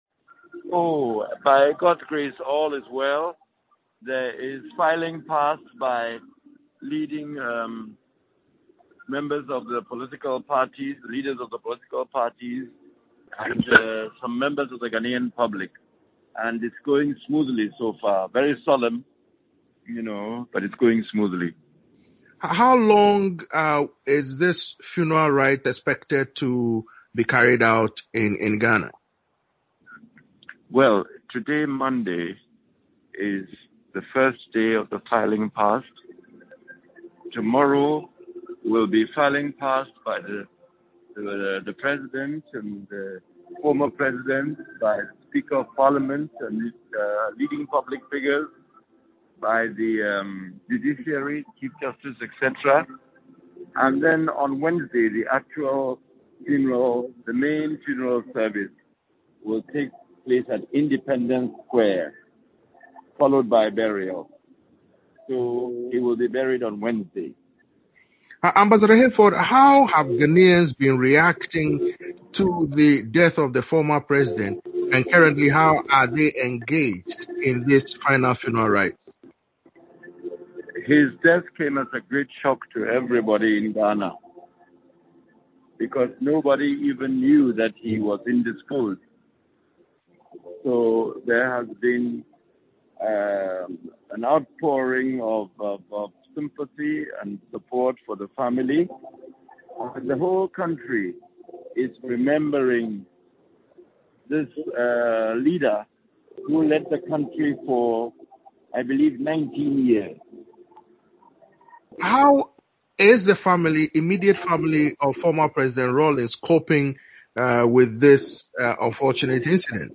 spoke to former Ghanaian envoy Joseph Hayford about Rawlings who died in November.